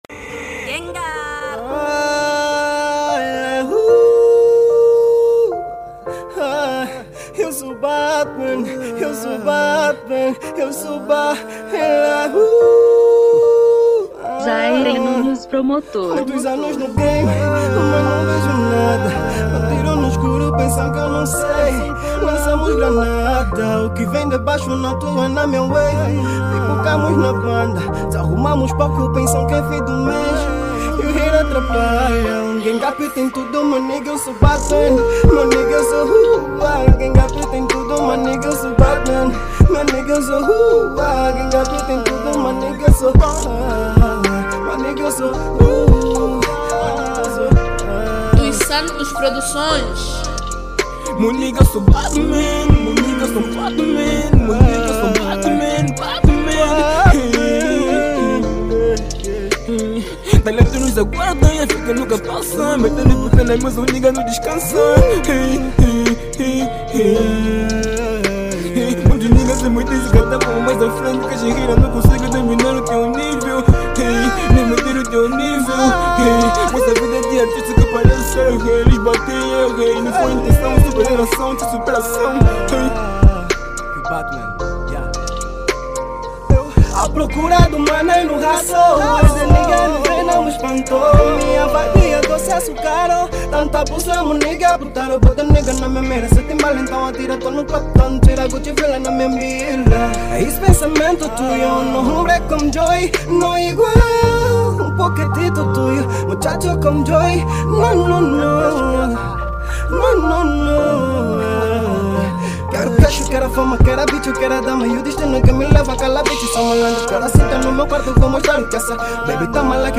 Estilo: Rap